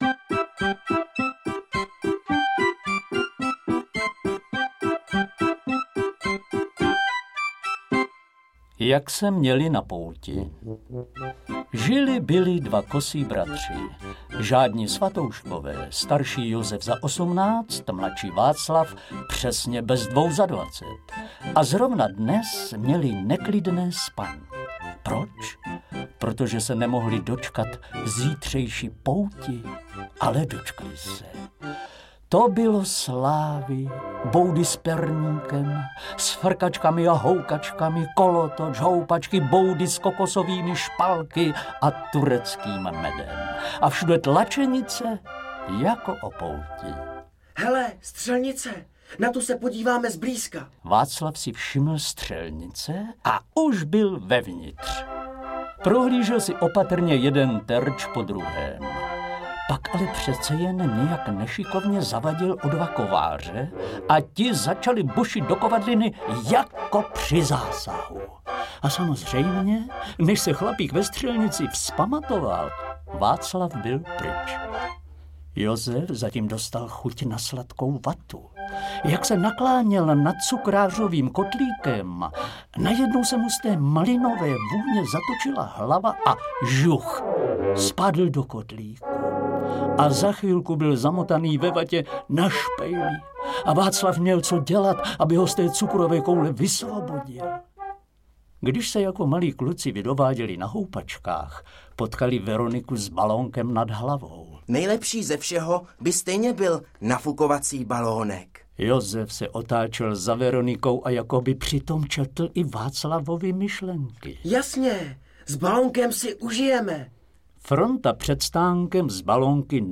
• AudioKniha ke stažení Velká kolekce pohádkových příběhů
Interpreti:  Eliška Balzerová, Jana Boušková, Vlastimil Brodský, Věra Galatíková, Antonie Hegerlíková, Lubomír Lipský, Jitka Molavcová, Jaroslav Satoranský, Petr Štěpánek, Petr Štěpánek